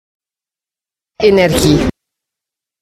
uitspraak Energie